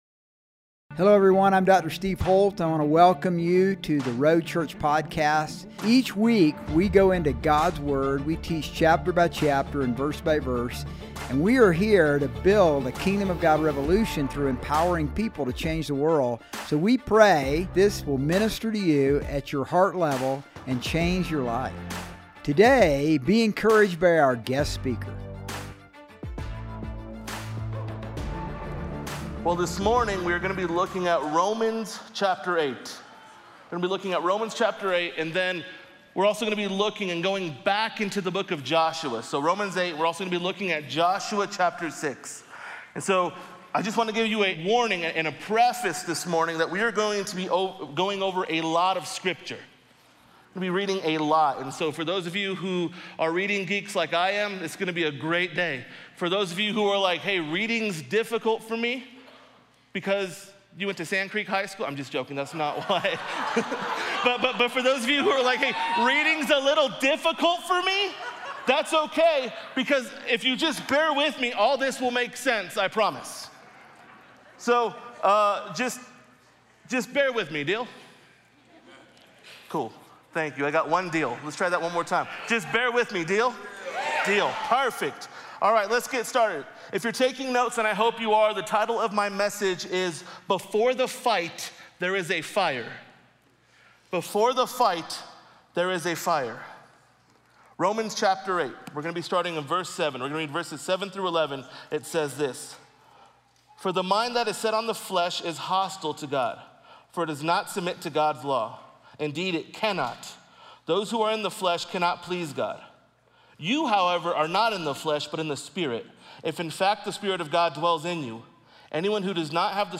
Sermons | The Road Church